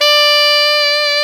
SAX ALTOMF0I.wav